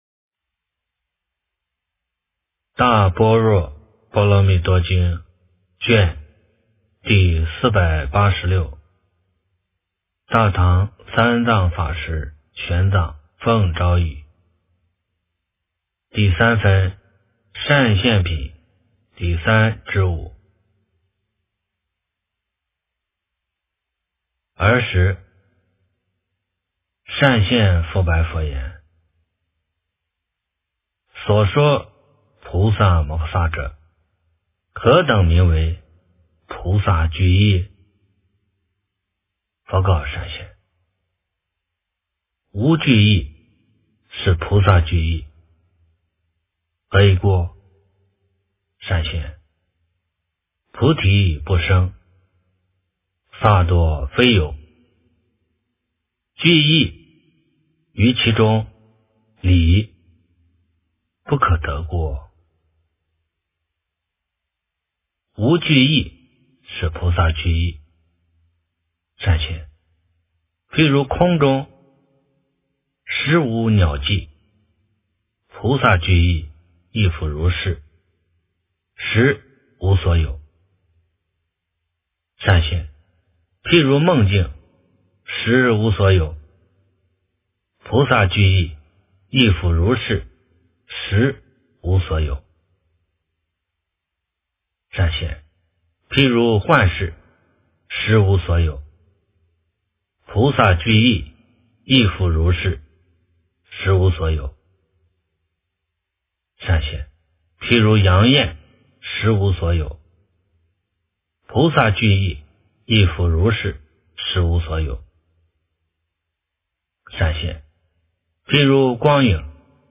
大般若波罗蜜多经第486卷 - 诵经 - 云佛论坛